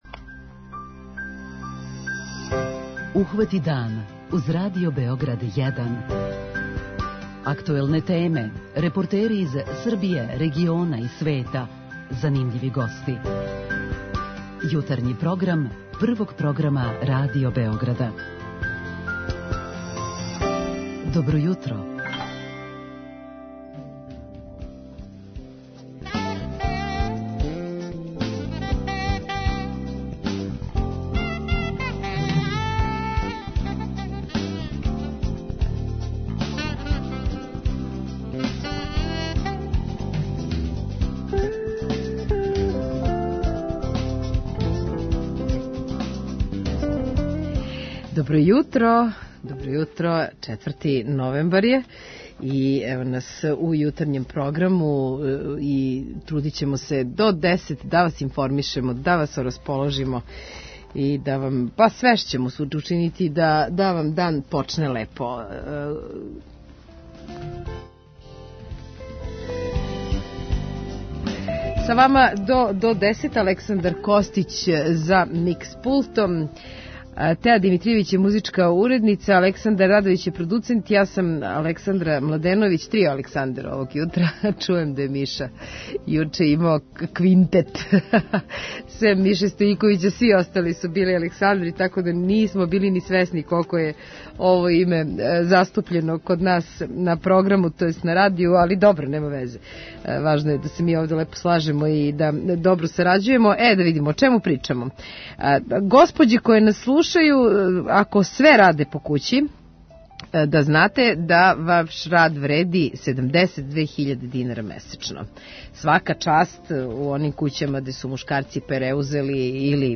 У неким породицама мушкарци су ти који раде кућне послове. преузми : 37.77 MB Ухвати дан Autor: Група аутора Јутарњи програм Радио Београда 1!